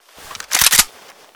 ak74_revival.ogg